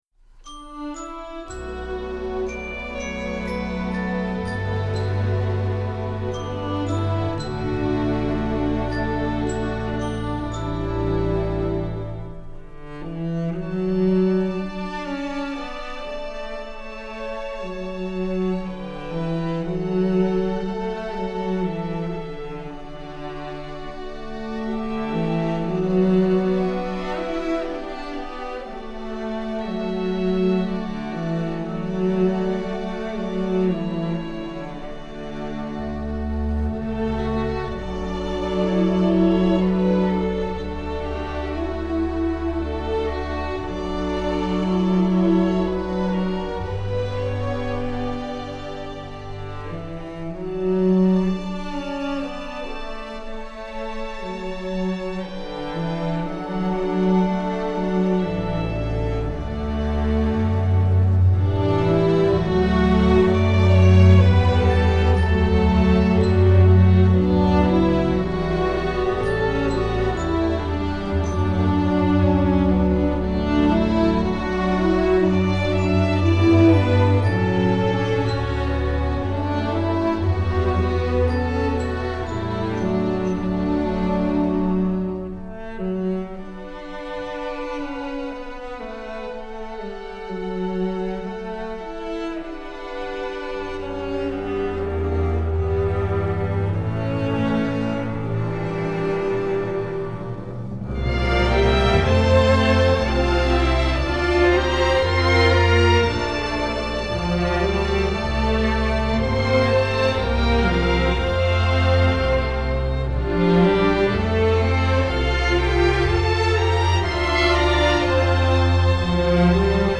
Composer: Irish Folk Song
Voicing: String Orchestra